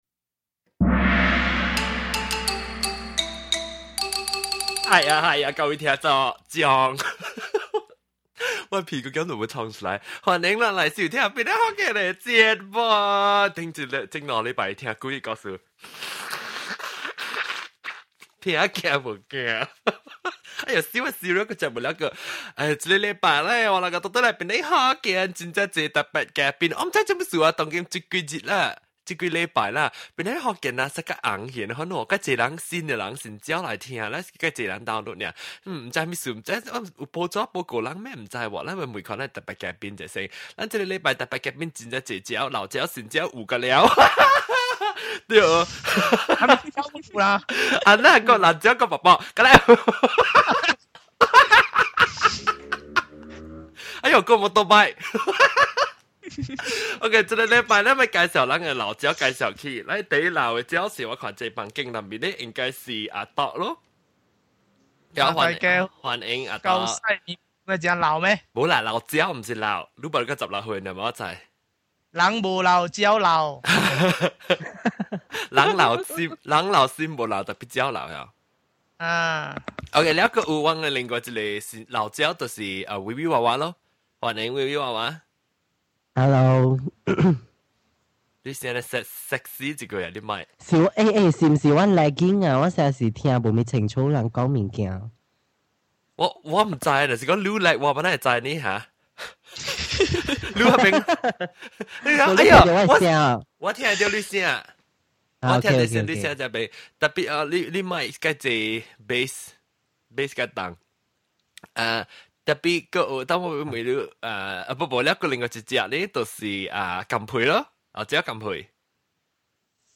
I have updated the file to mono, and it should be half the size.
This week we have our guests talk about taking baths. From giving babies a bath all the way to what shampoo we use.